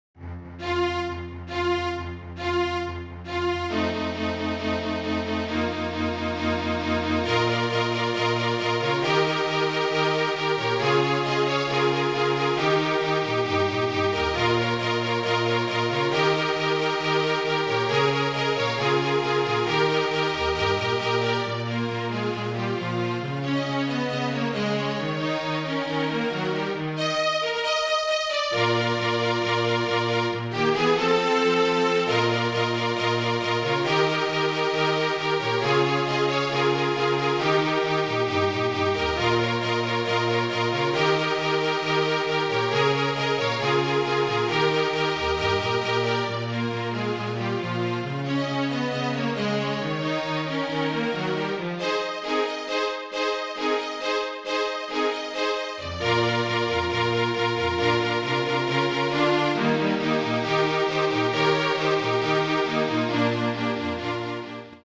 FLUTE TRIO
Flute, Violin and Cello (or Two Violins and Cello)